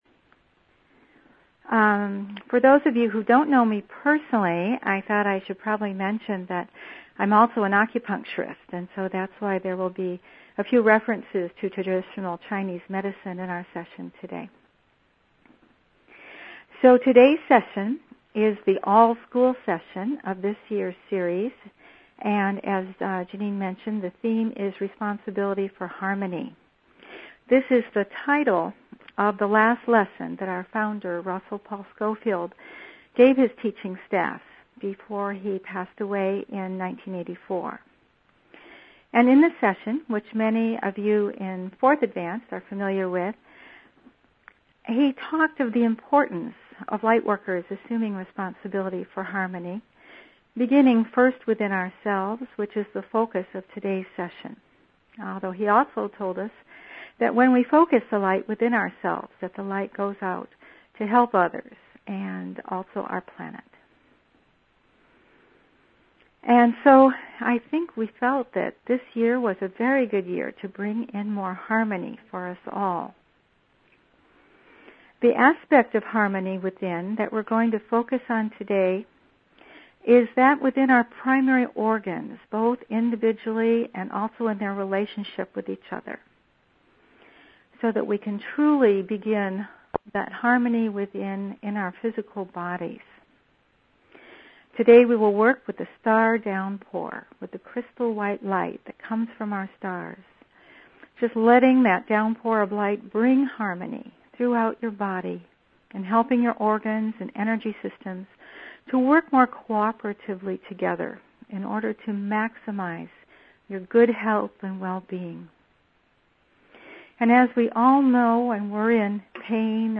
Responsibility for Harmony - Actualism Webinar